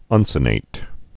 (ŭnsə-nāt, -nĭt)